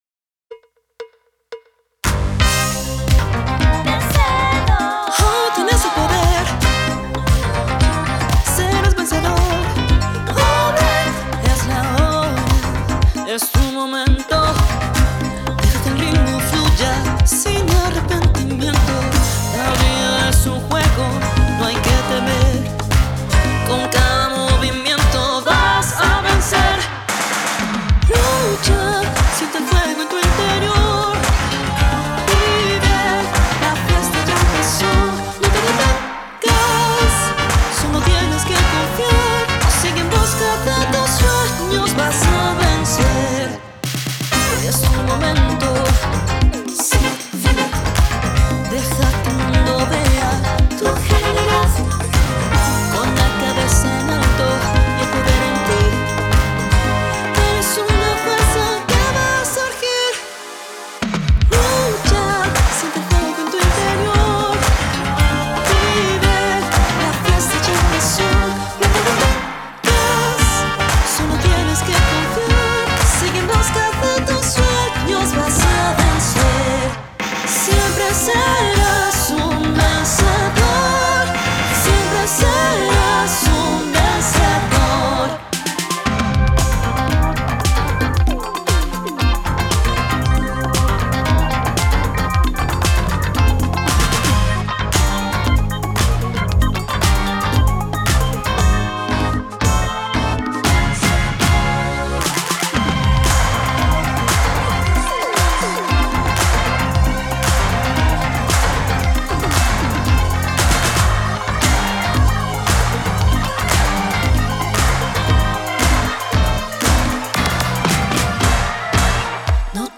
Funk colombiano
Emulación analógica